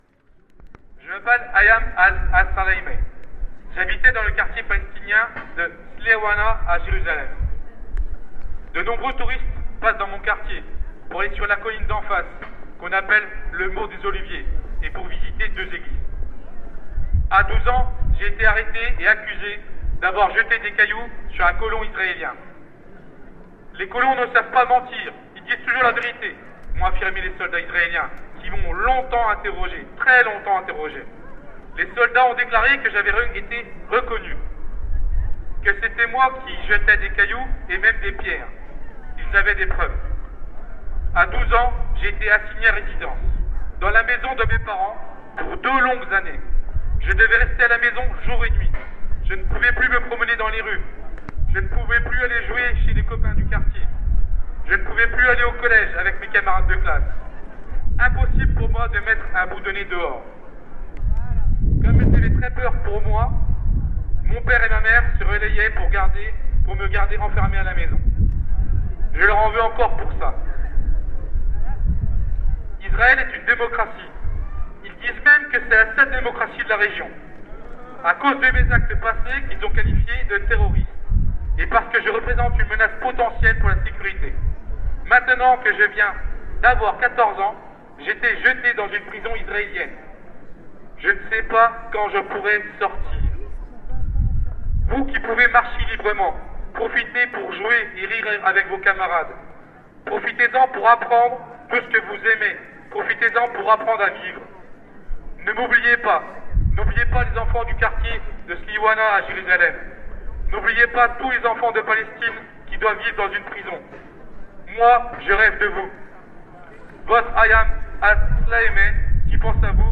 Enregistrements des prises de paroles et des slogans de la manifestation.
Lecture d’une lettre de Palestine
Place du Bareuzai,